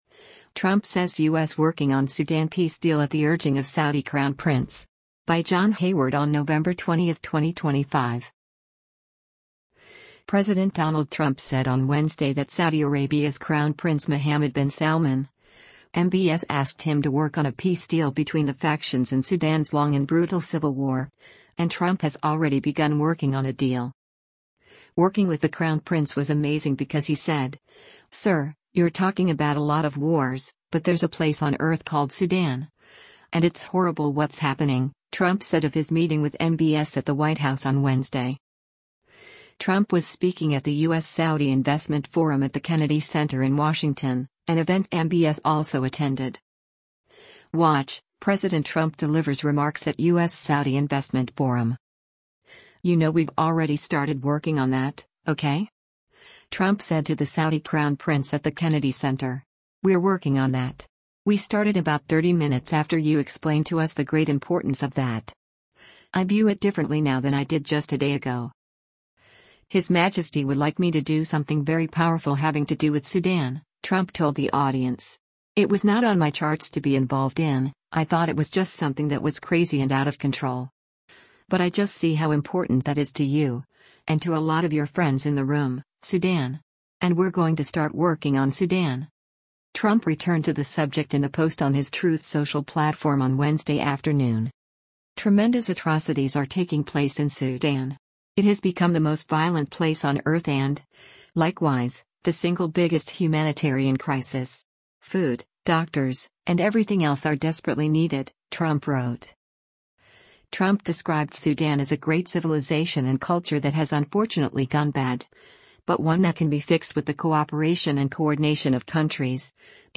Trump was speaking at the U.S.-Saudi Investment Forum at the Kennedy Center in Washington, an event MBS also attended.
WATCH — President Trump Delivers Remarks at U.S.-Saudi Investment Forum: